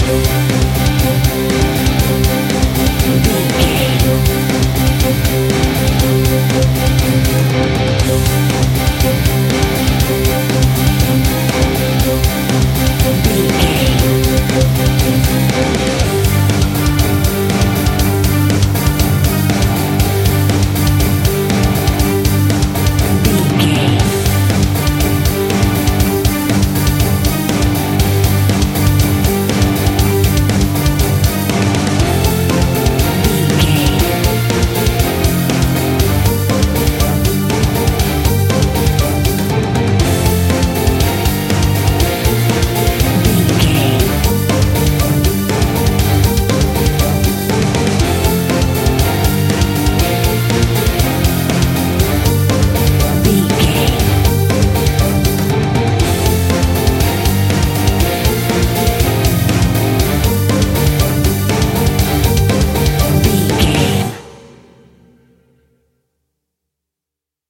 Epic / Action
Fast paced
Aeolian/Minor
horror rock
instrumentals
Heavy Metal Guitars
Metal Drums
Heavy Bass Guitars